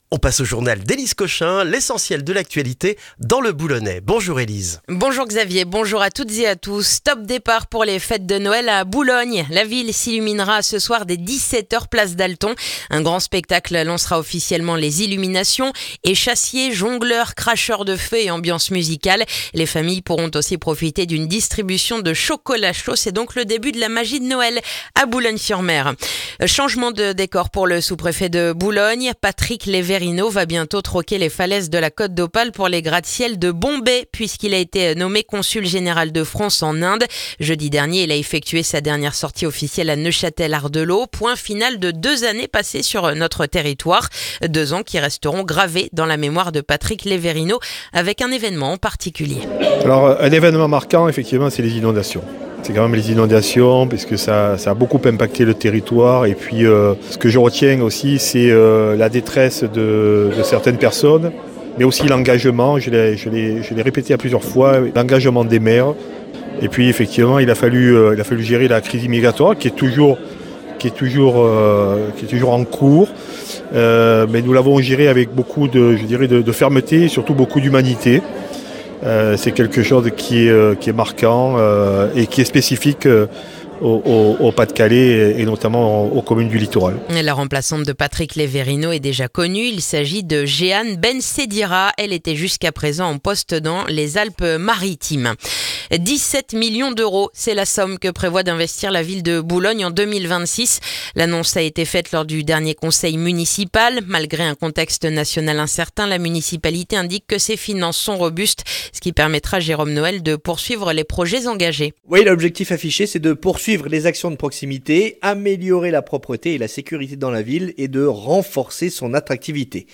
Le journal du mercredi 26 novembre dans le boulonnais